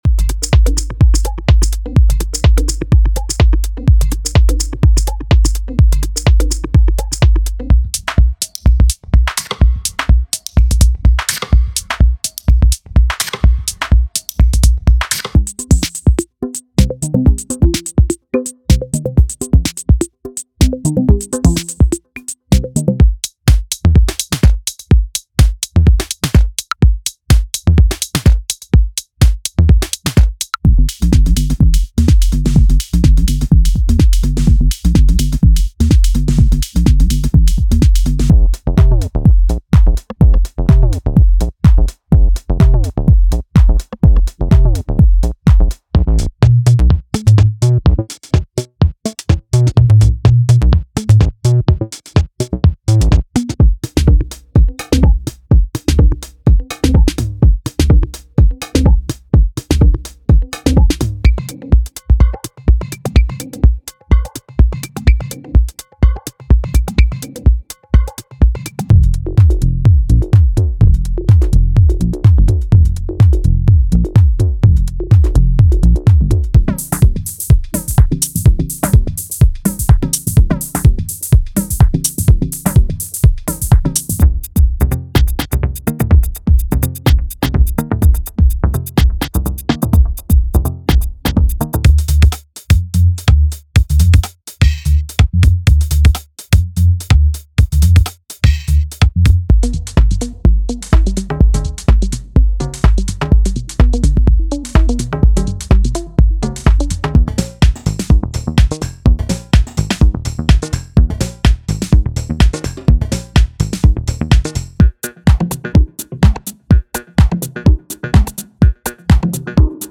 デモサウンドはコチラ↓
Genre:Minimal Techno
• 100 Drum loops
• 50 Ableton Live Bass presets
• 187 Synth & Pad presets for Ableton Live